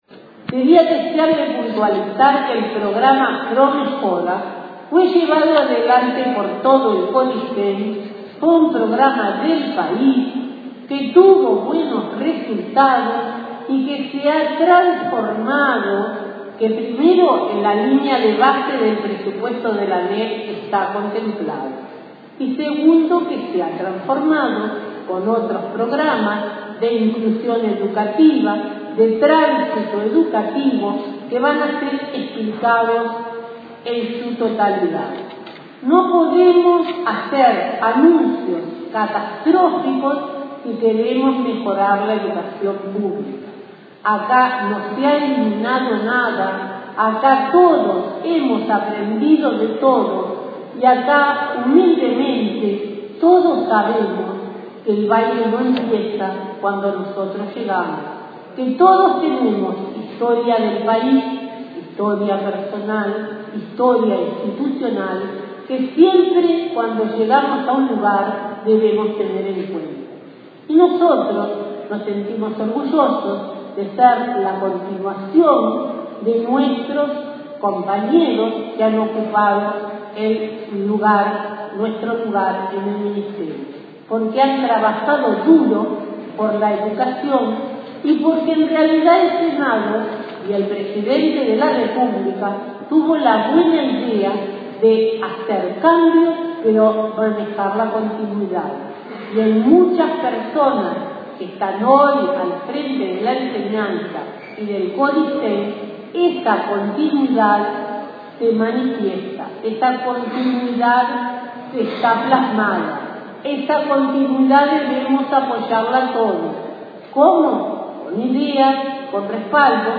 La ministra de Educación y Cultura, María Julia Muñoz, quien se encuentra desde esta mañana en el Parlamento a pedido del senador nacionalista Jorge Larrañaga, aseguró que el programa "ProMejora" se ha llevado adelante con buenos resultados y se ha transformado "en otros programas de inclusión y tránsito educativo".